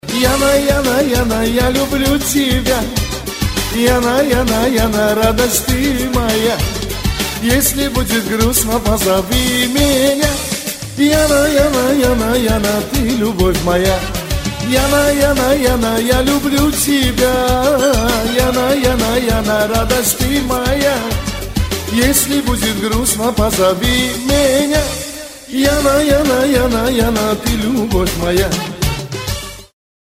• Качество: 256, Stereo
поп
Кавсказские